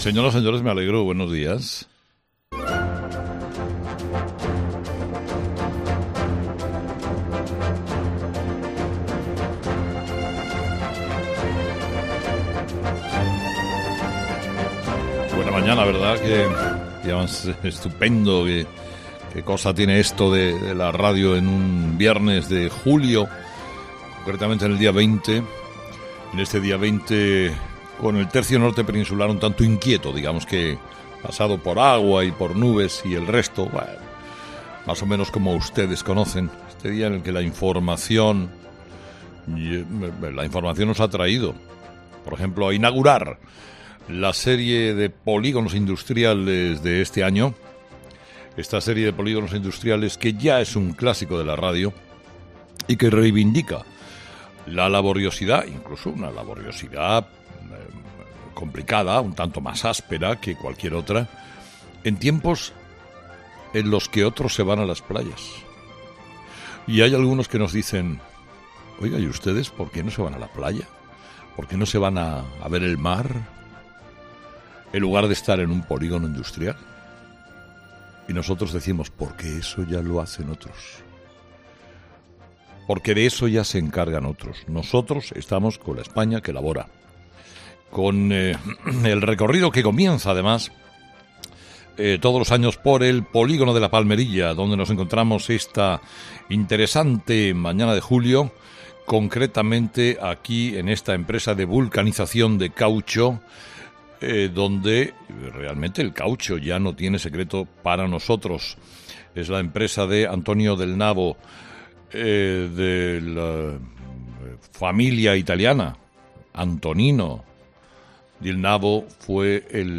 Herrera inicia la ruta de polígonos industriales de este verano en una fábrica de caucho
Monólogo de las 8 de Herrera